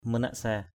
/mə-naʔ-sa:/ manaksa mnKx% [Cam M] (đg.) suy nghĩ, lý giải = réfléchir = to think, to reflect. gruk nan manaksa oh hu g~K nN mnKx% oH h~% việc đó lý giải không nổi...
manaksa.mp3